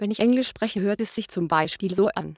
CHATR's German synthesis)